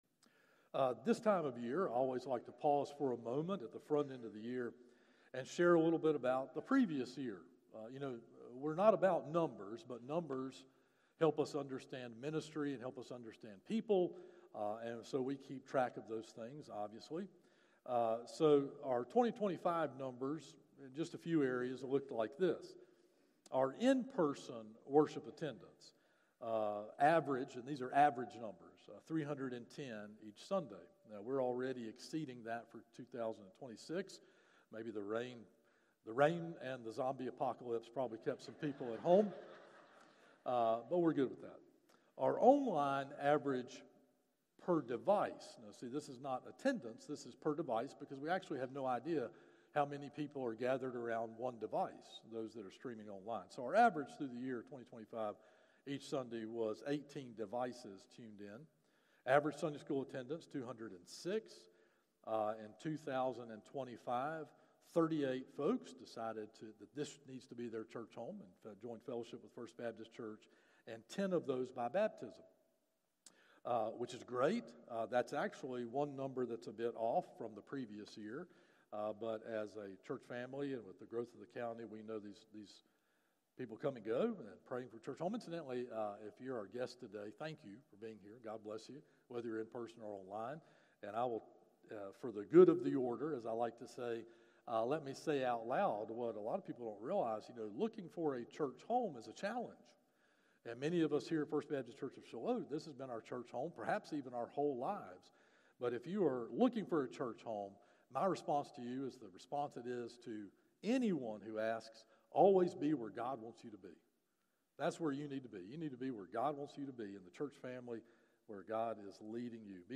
Morning Worship - 11am